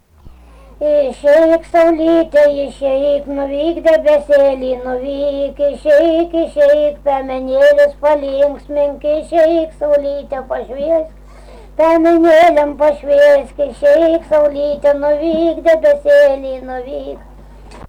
smulkieji žanrai
Rageliai
vokalinis